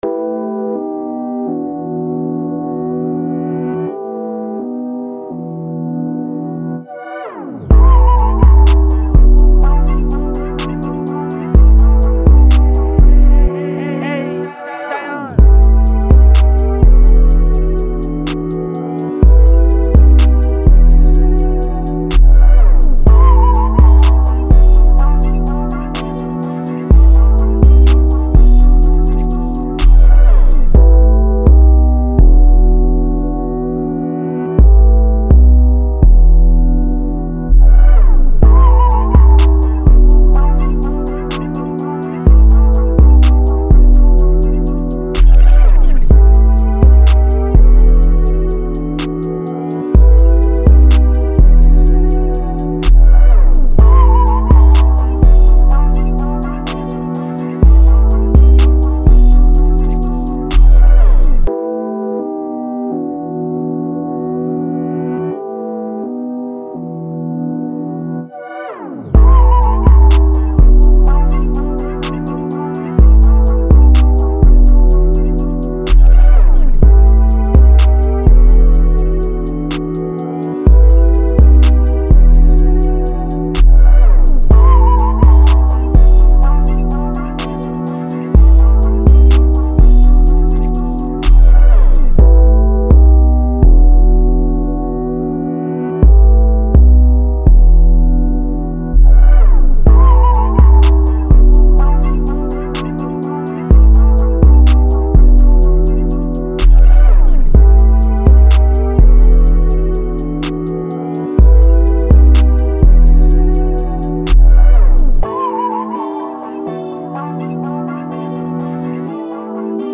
New Jazz
125 A Minor